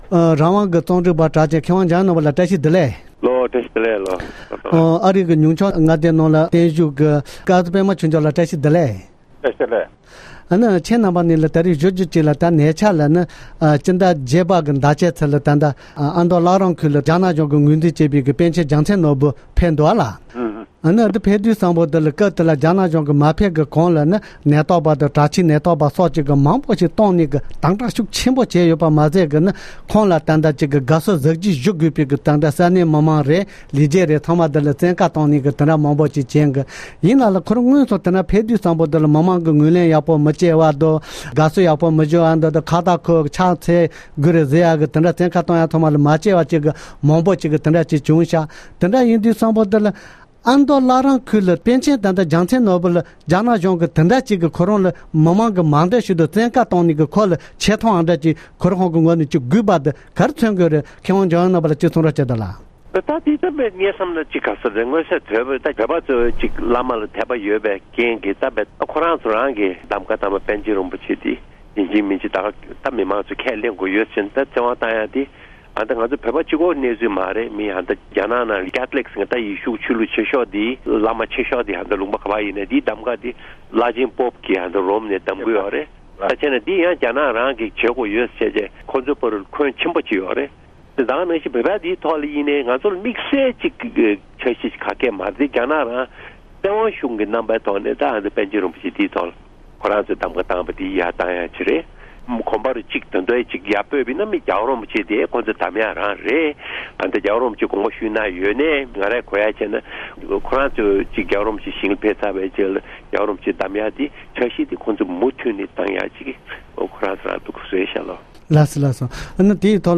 སྒྲ་ལྡན་གསར་འགྱུར།
གནས་འདྲི་